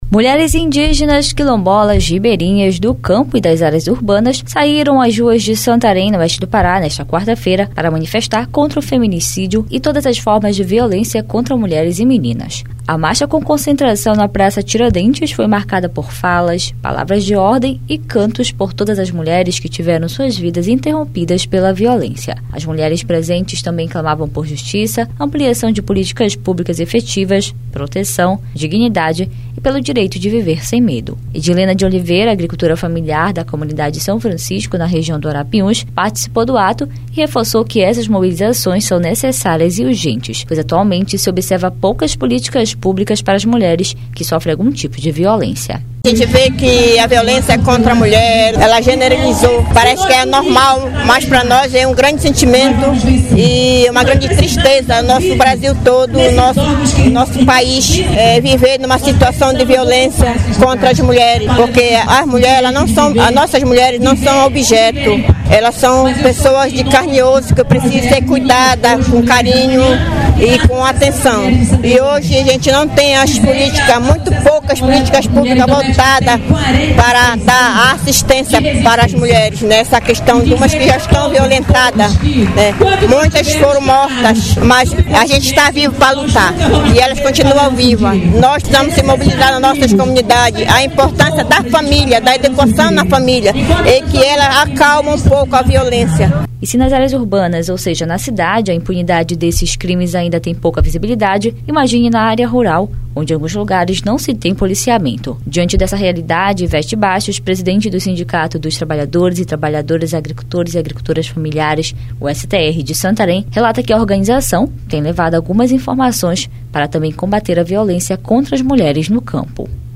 Mulheres indígenas, quilombolas, ribeirinhas, do campo e das áreas urbanas saíram às ruas de Santarém, oeste do Pará nesta quarta-feira(11) para manifestar contra o feminicídio e todas as formas de violência contra mulheres e meninas. A Marcha com concentração na Praça Tiradentes foi marcada por fala, palavras de ordem e cantos por todas as mulheres…